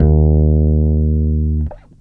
17-D#2.wav